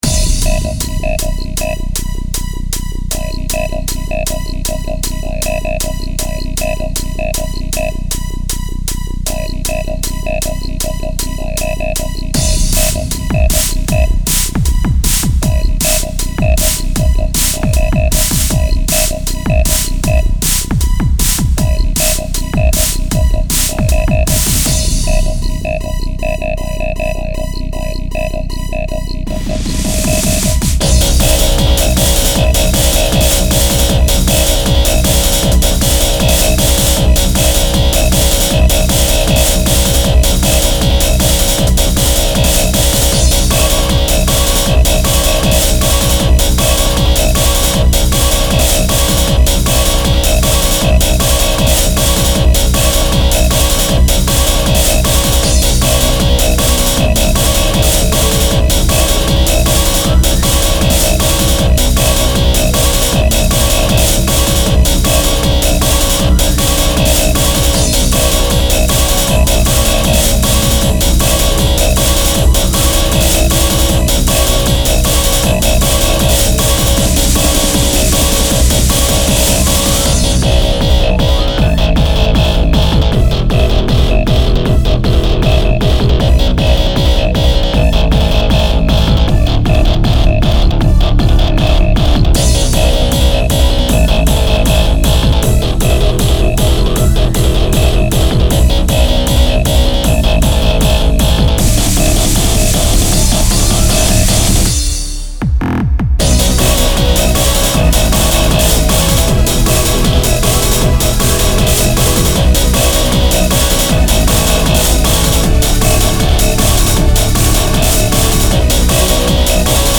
Genre：Epic Trance